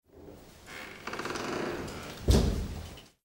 Abrir el portón de madera de un palacio
portón
Sonidos: Acciones humanas